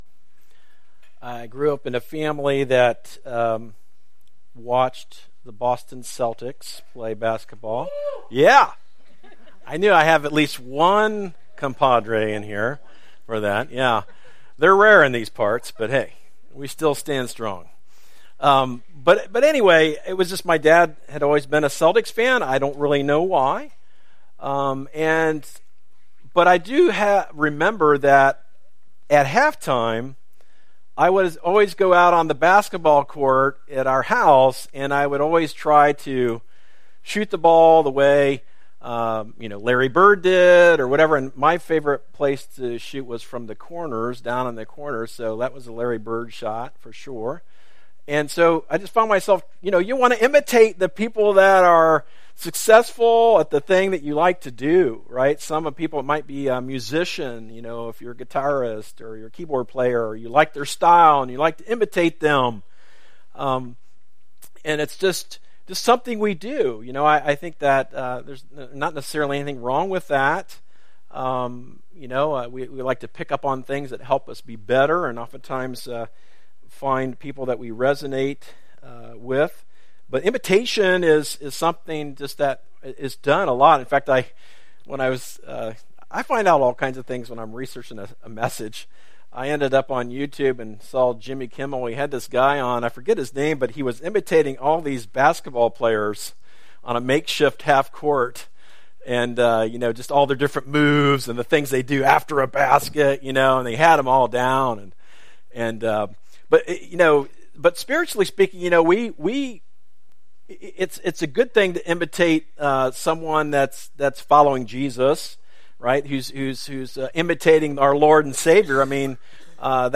A message from the series "Church Matters."